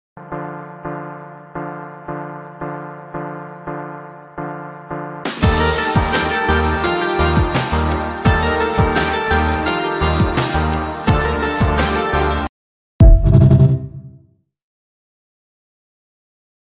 ژانر: پاپ
🎵 نام آهنگ : بیکلام